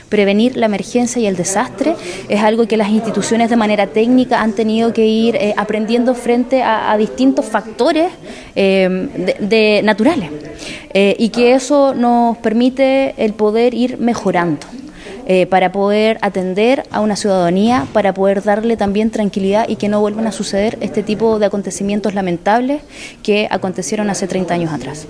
La delegada Presidencial de Los Lagos, Paulina Muñoz, fue enfática al señalar en que se han aprendido lecciones que han derivado en trabajos de mejoramiento.